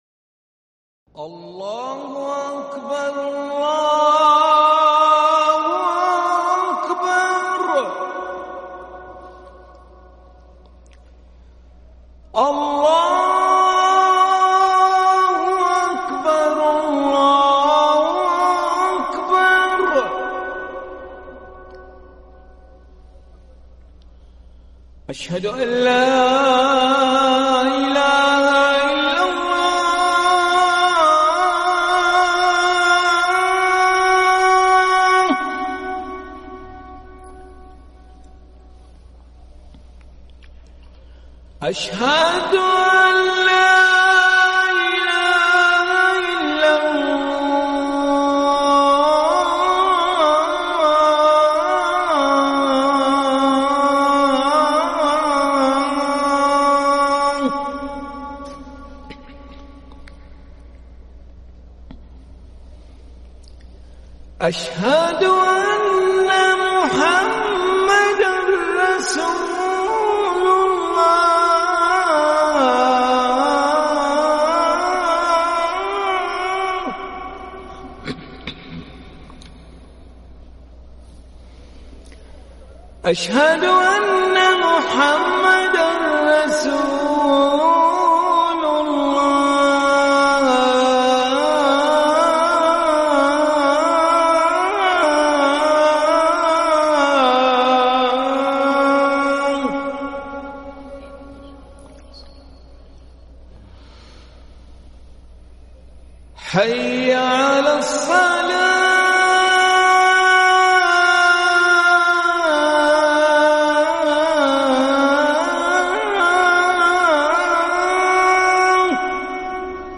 اذان العشاء للمؤذن علي ملا الخميس 3 محرم 1443هـ > ١٤٤٣ 🕋 > ركن الأذان 🕋 > المزيد - تلاوات الحرمين